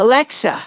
synthetic-wakewords
ovos-tts-plugin-deepponies_Teddie_en.wav